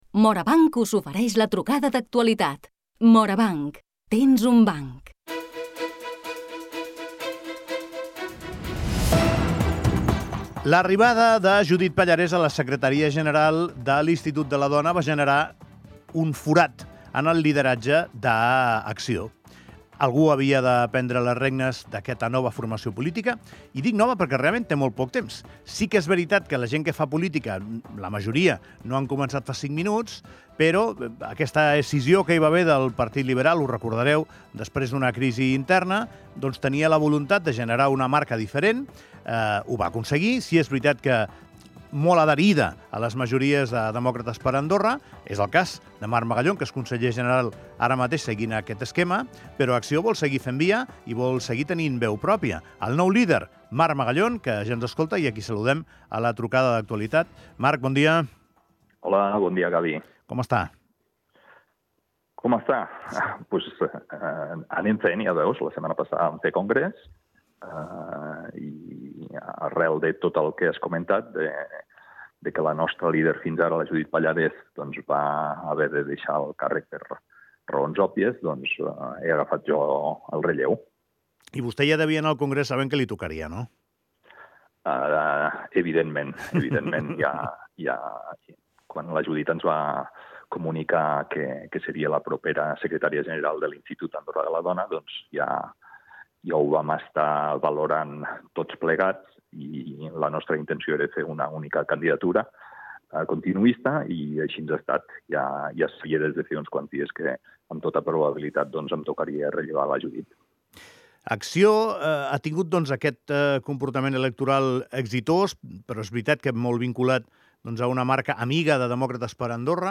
Avui fem la trucada d'actualitat amb el nou líder d'Acció, el conseller general Marc Magallón. La sortida de Judith Pallarès ha precipitat el seu ascens a la presdiència de la formació.